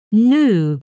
✓ [gnu:]